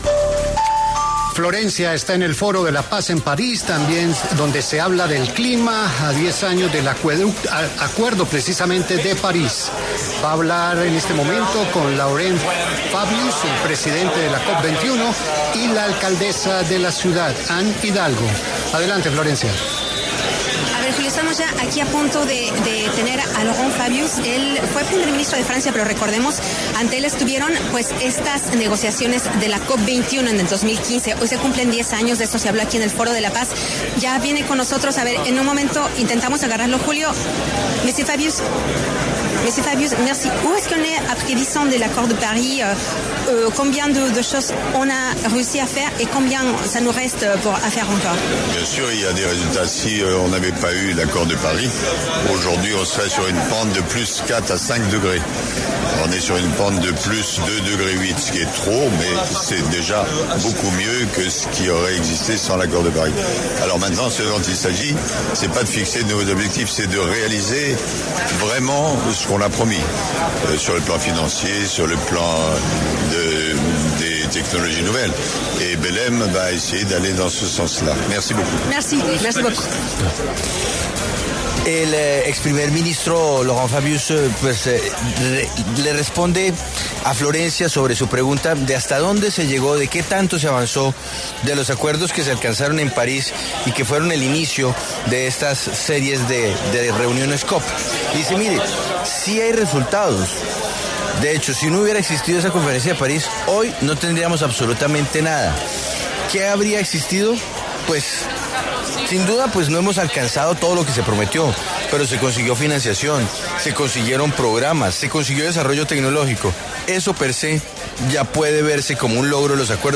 Laurent Fabius, ex primer ministro de Francia y presidente de la COP21, y Anne Hidalgo, alcaldesa de París, conversaron con La W sobre la implementación del Acuerdo de París.
W Radio asistió al Foro de la Paz en París, donde conversó con Laurent Fabius, ex primer ministro de Francia y presidente de la COP21, quien se refirió a lo avanzado en los acuerdos.